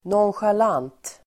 Uttal: [nånsjal'an:t (el. -'ang:t)]